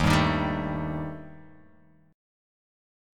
D#Mb5 chord